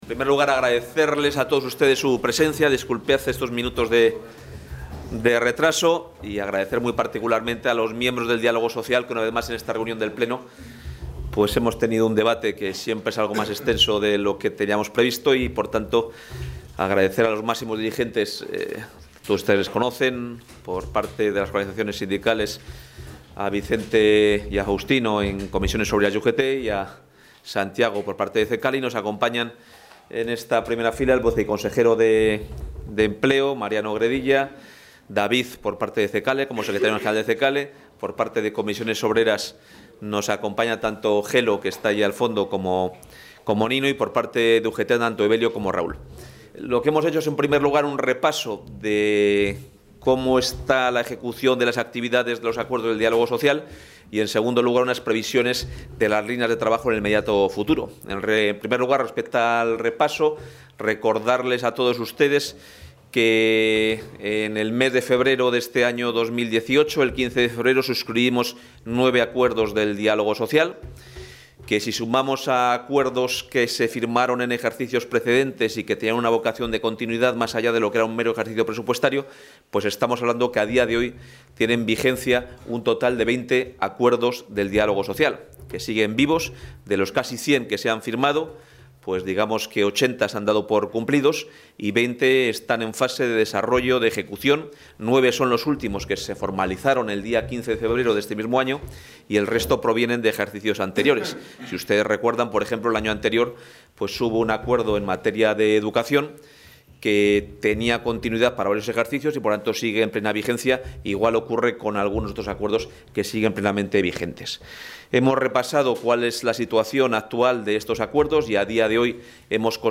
Intervención del consejero de Empleo.